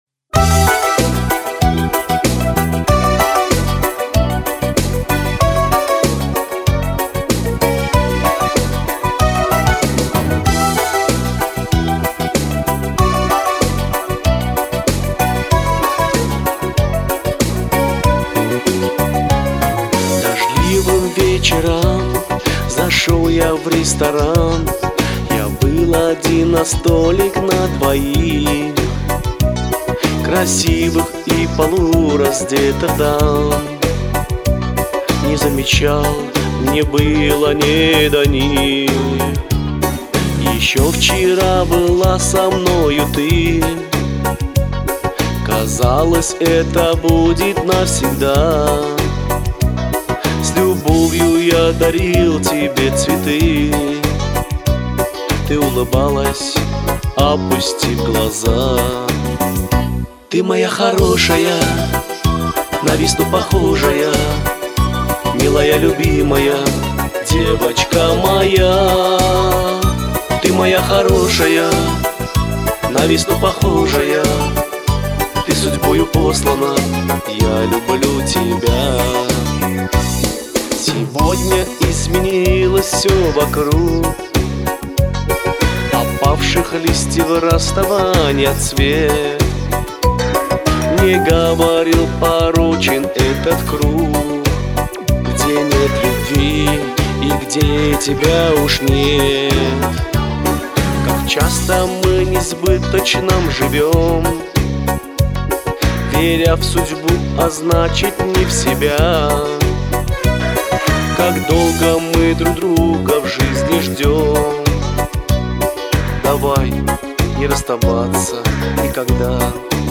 Такая песня нежная легкая, очень понравилась.